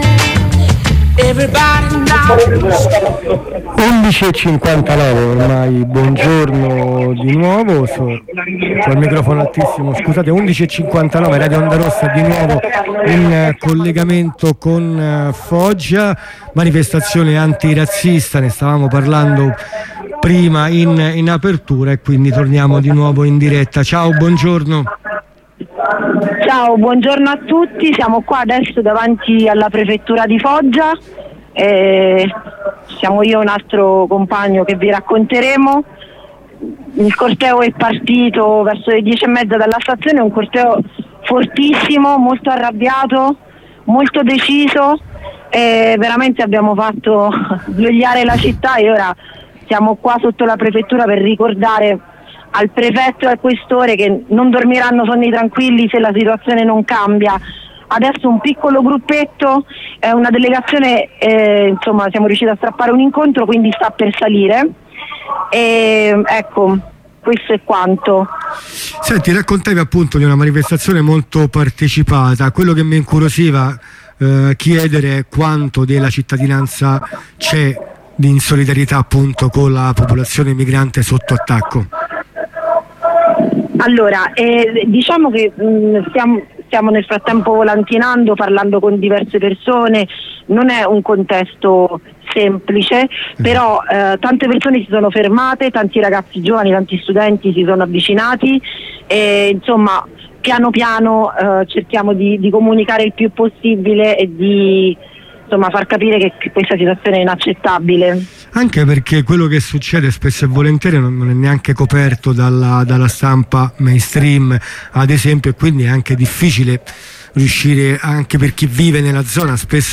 Ne abbiamo parlato con alcun compagni/e, prima della manifestazione e durante il presidio sotto la prefettura.